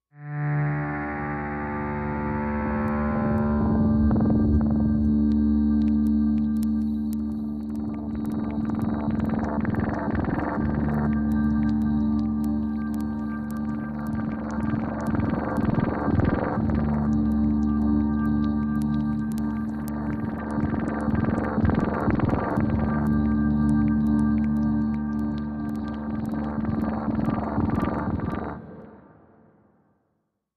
Pulse Steady Repeating Mid Pulse Sweep Echoes